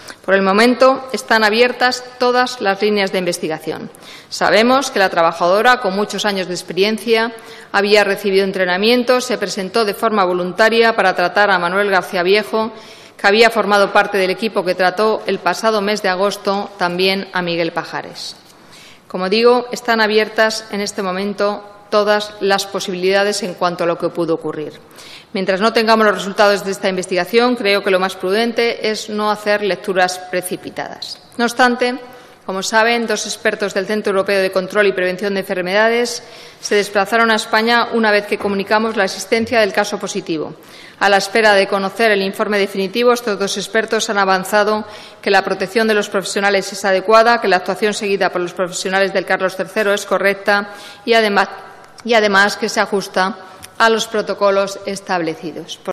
Comparecencia de Ana Mato en la Comisión de Sanidad 15/10/2014